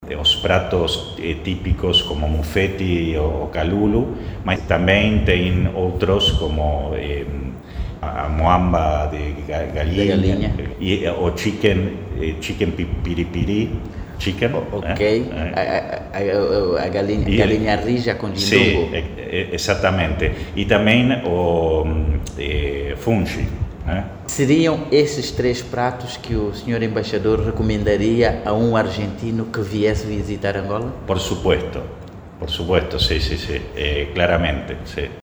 Em entrevista exclusiva ao programa Casa da Rádio, do Grupo RNA, Guillermo Nicolás disse que os sabores angolanos conquistam os visitantes e reflectem a riqueza cultural do país.